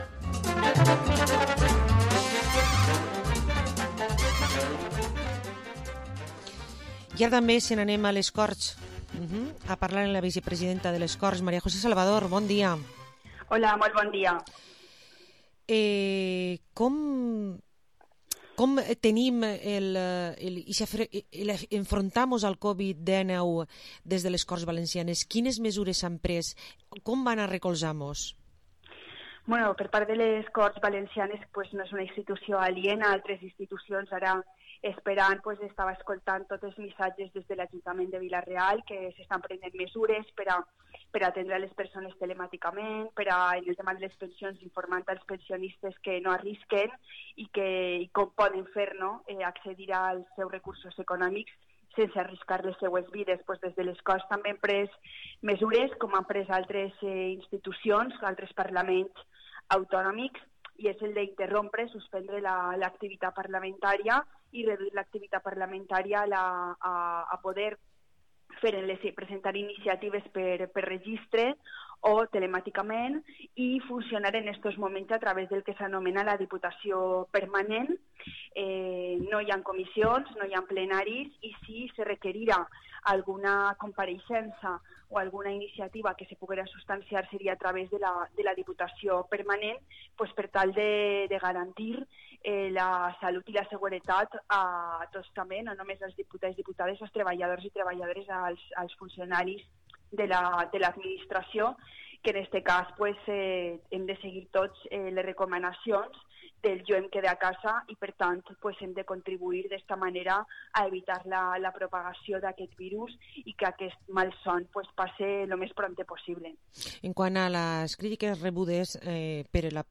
Entrevista a la Vicepresidenta de Les Corts Valencianes, Mª José Salvador